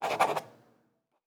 Fantasy Interface Sounds
Writing 3.wav